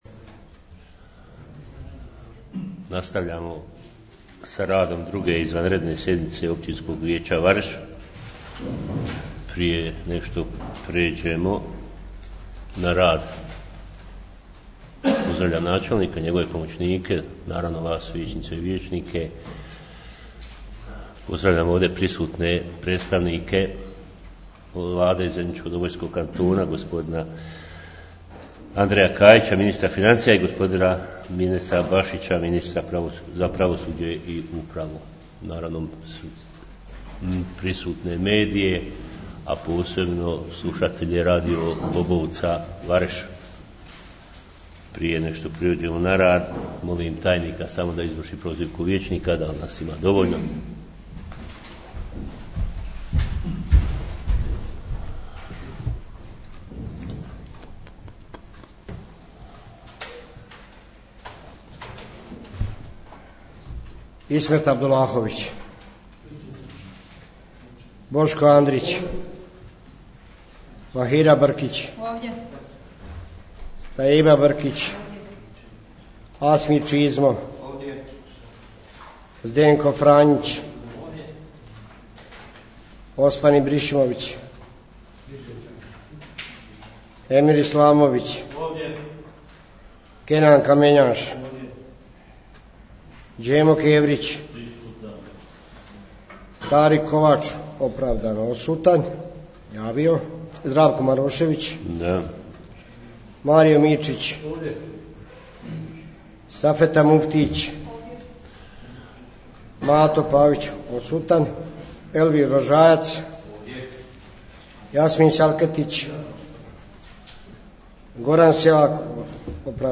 U nastavku poslušajte što se sve dešavalo na nastavku prekinute 2. izvanredne sjednice Općinskog vijeća....